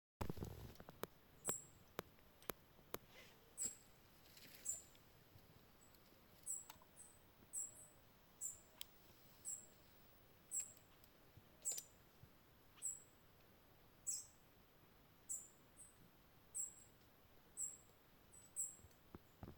Pepitero Picudo (Saltator maxillosus)
Nombre en inglés: Thick-billed Saltator
Condición: Silvestre
Certeza: Fotografiada, Vocalización Grabada